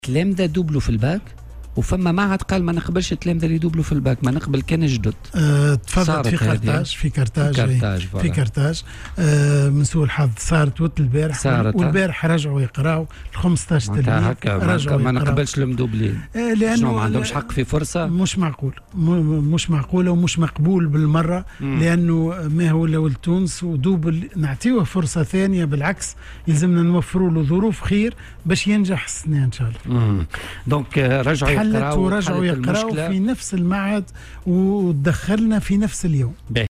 وأضاف في مداخلة له اليوم على "الجوهرة أف أم" أن هذا التصرّف غير مقبول وأنه على العكس يجب إعطائهم فرصة ثانية وتوفير أفضل الظروف لهم.